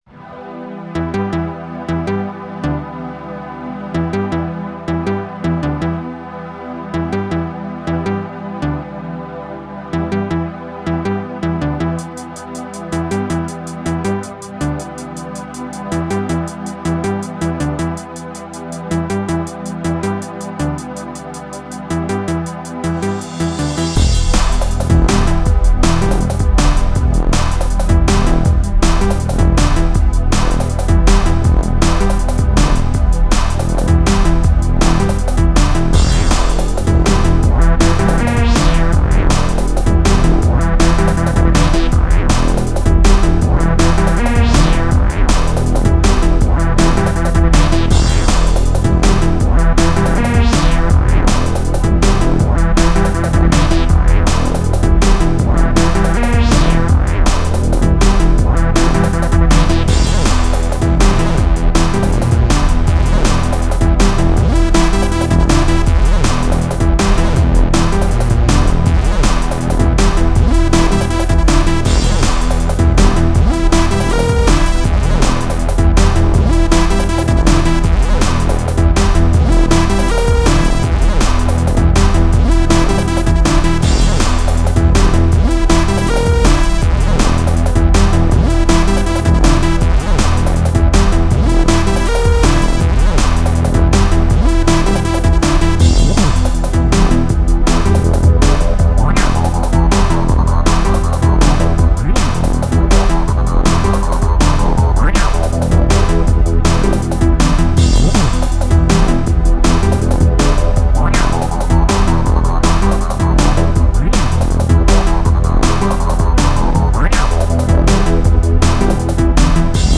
11 channels XM tracked in Milky Tracker